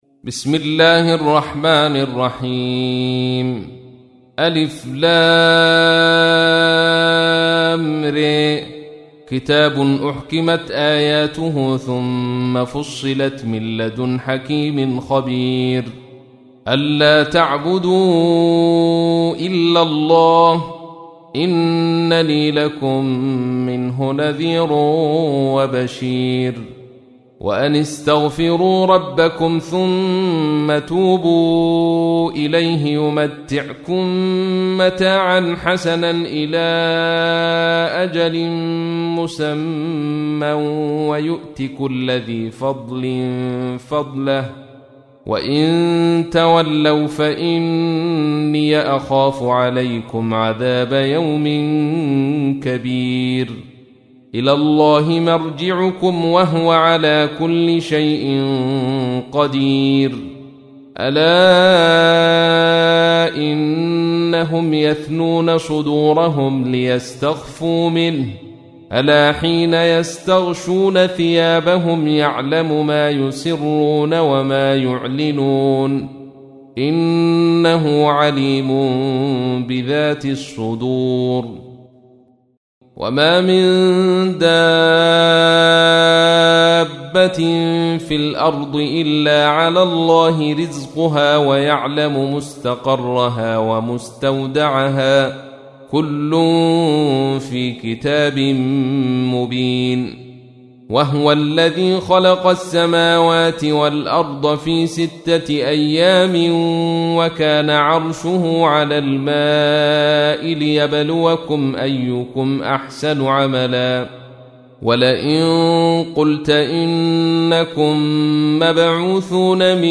تحميل : 11. سورة هود / القارئ عبد الرشيد صوفي / القرآن الكريم / موقع يا حسين